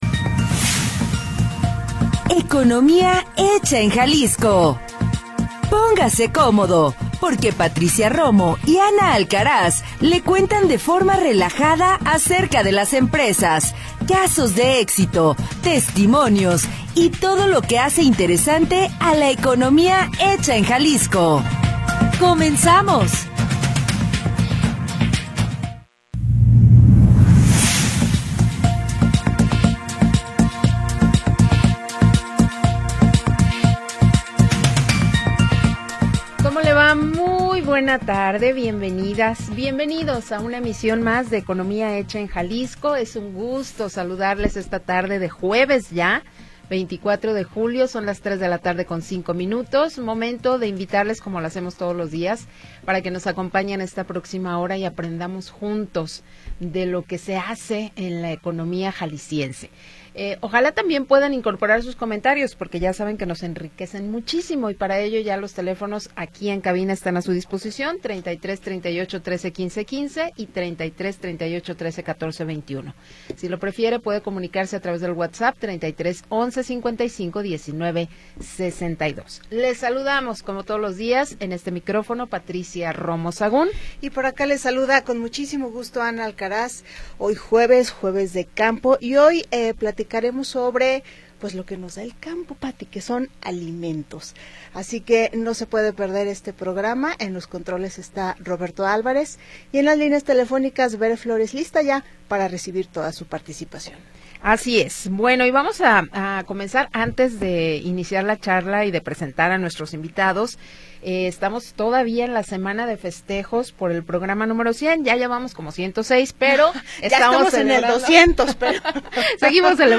de forma relajada